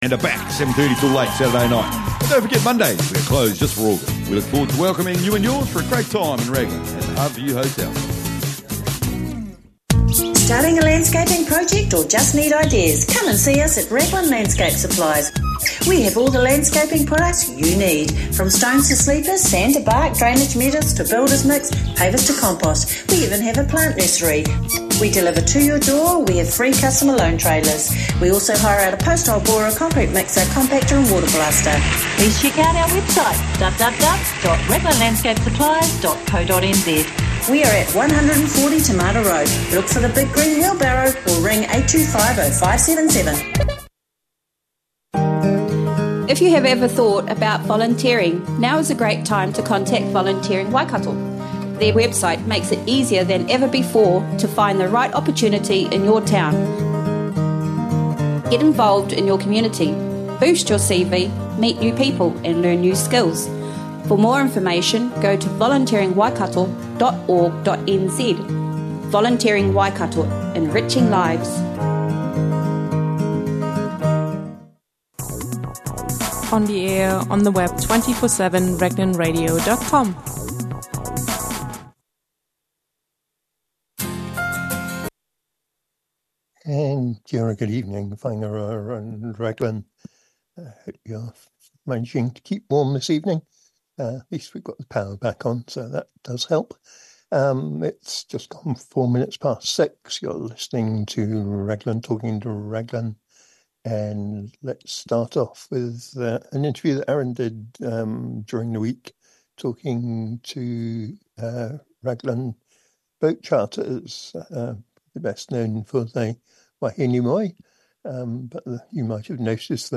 Raglan Community Radio